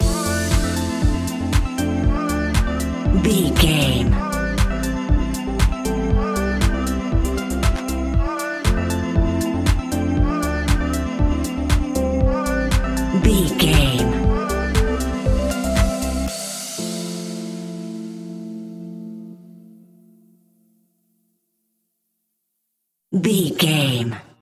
royalty free music
Ionian/Major
groovy
uplifting
driving
energetic
bouncy
electric piano
synthesiser
drum machine
vocals
electronic
synth leads
synth bass